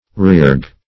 Search Result for " reargue" : The Collaborative International Dictionary of English v.0.48: Reargue \Re*ar"gue\, Re-argue \Re-ar"gue\(r[=e]*[aum]r"g[=u]), v. t. To argue anew or again; as, This politician will be forced into re-arguing an old national campaign.